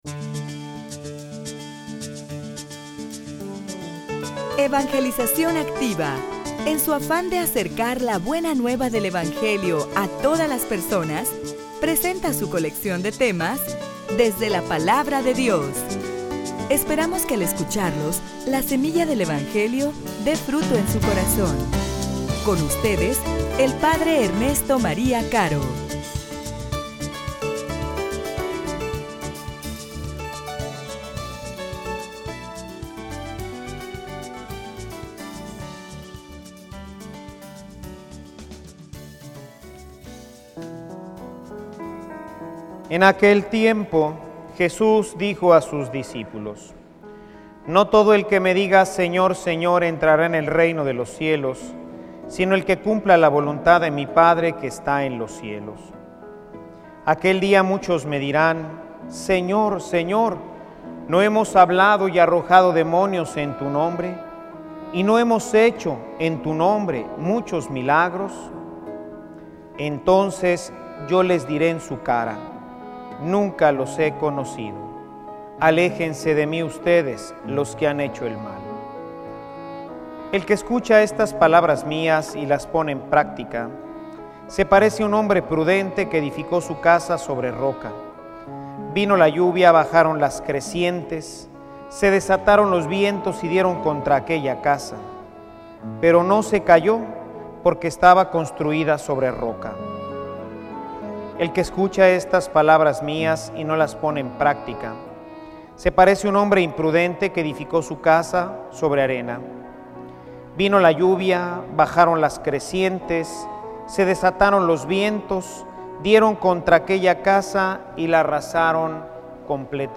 homilia_Tu_palabra_ilumina_mi_camino.mp3